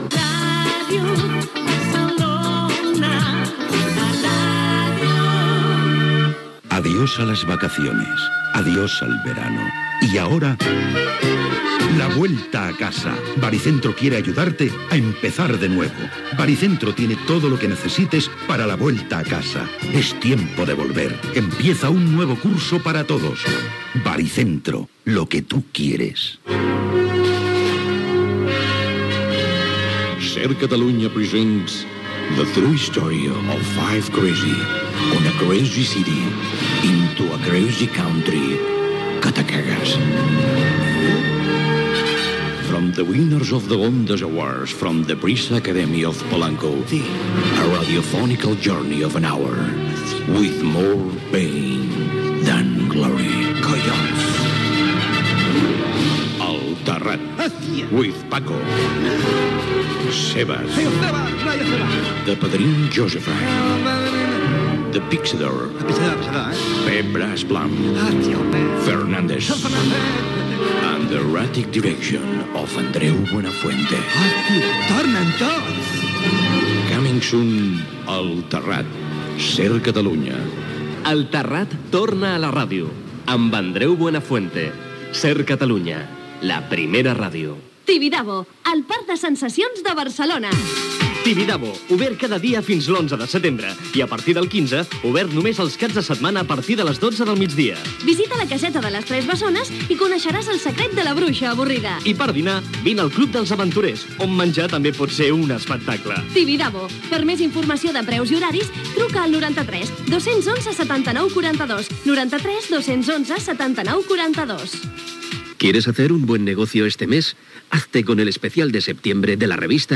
Indicatiu de l'emissora, publicitat, promoció del programa "El terrat" (veu d'Andreu Buenafuente), publicitat, promoció del programa "La contraportada" (Especialistas secundarios), indicatiu de l'emissora
FM